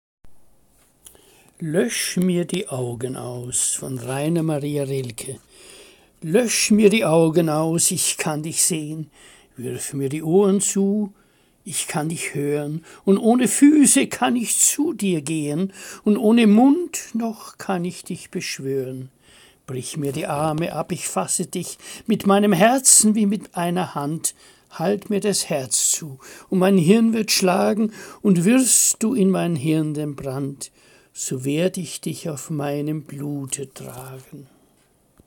Lesung - Musikvideo